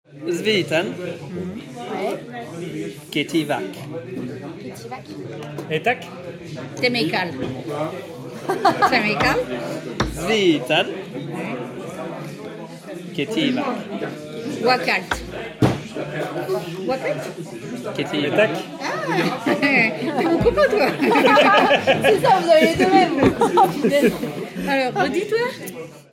On voit des gens s’adresser à d’autres avec des mots improbables :
Un petit extrait d’une partie en live, ça vous dit ?